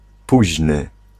Ääntäminen
IPA: /laːt/